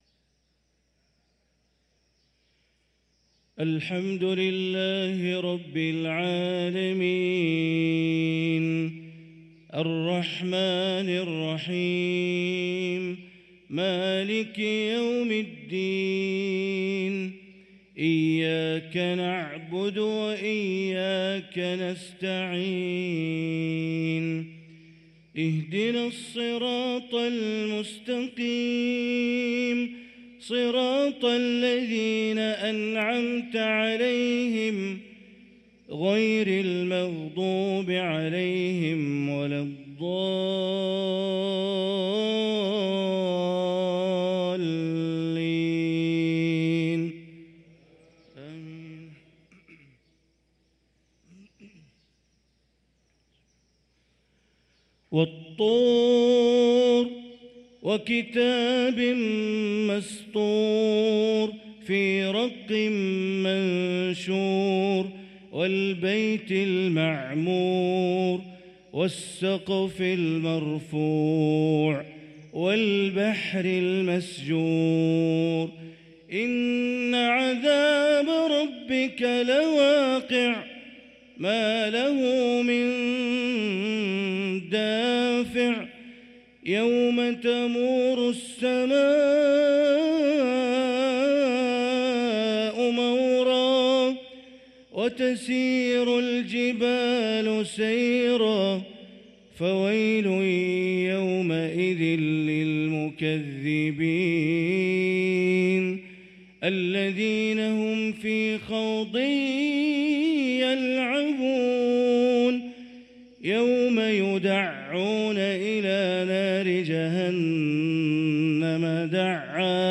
صلاة المغرب للقارئ بندر بليلة 29 جمادي الآخر 1445 هـ
تِلَاوَات الْحَرَمَيْن .